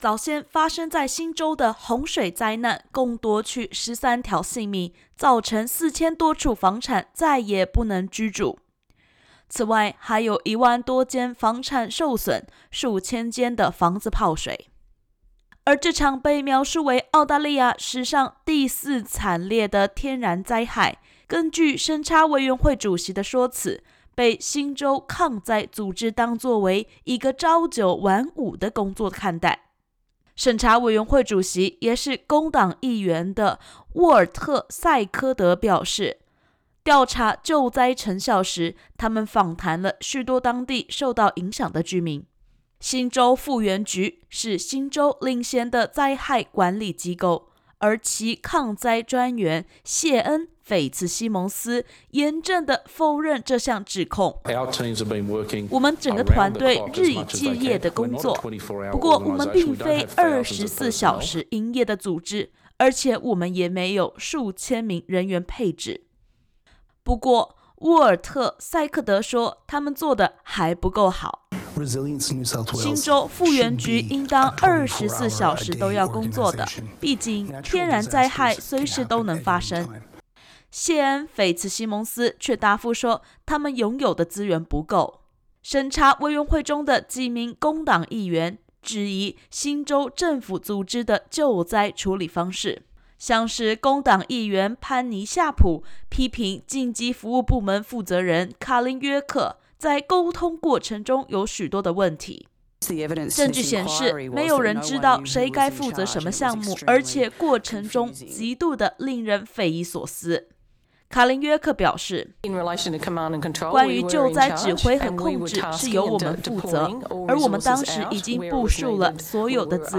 今年早些时候新州发生了洪水灾难，为此，新州灾害管理机构为他们的救援和重建工作，进行了辩护。（点击首图收听完整采访音频）